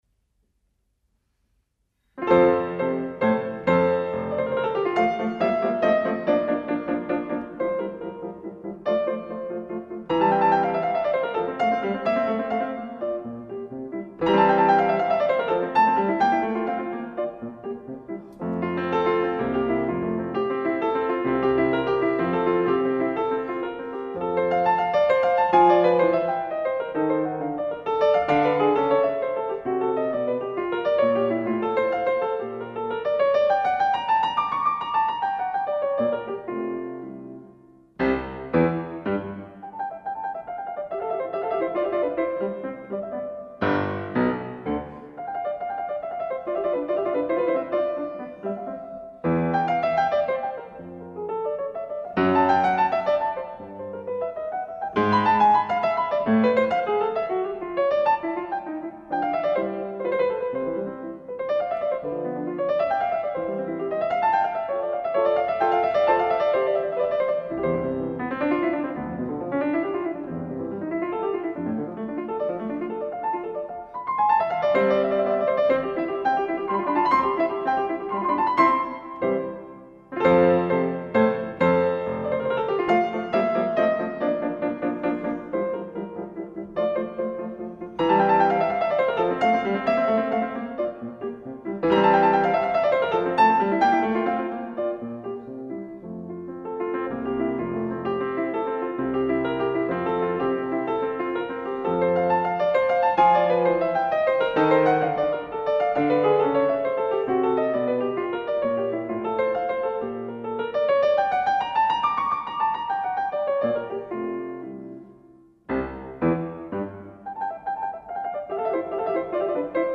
Audio (live)
Live on February 2008